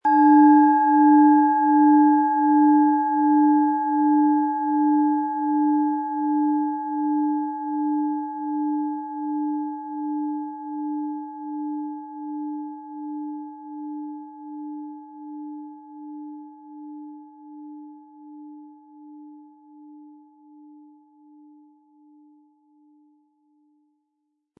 Planetenton 1
Diese von  Hand getriebene Planetentonschale Saturn wurde in einem kleinen indischen Dorf gefertigt.
Um den Originalton der Schale anzuhören, gehen Sie bitte zu unserer Klangaufnahme unter dem Produktbild.
PlanetentonSaturn
MaterialBronze